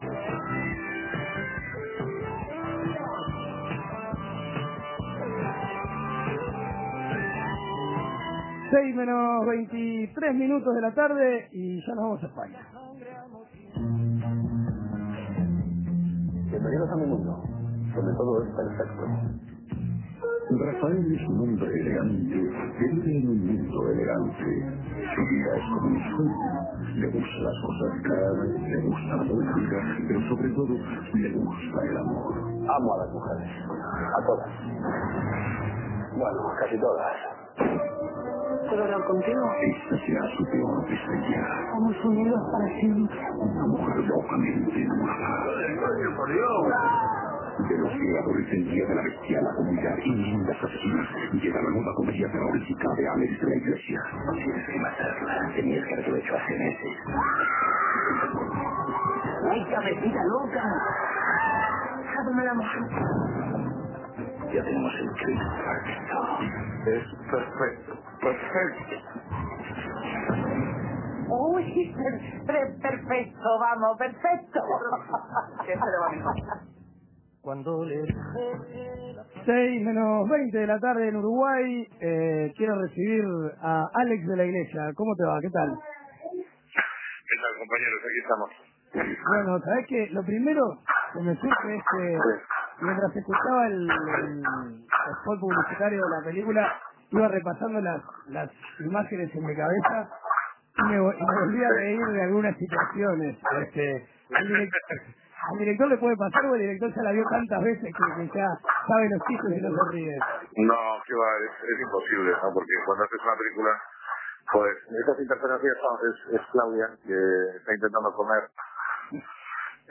El director español dialogó en exclusiva en 13a0.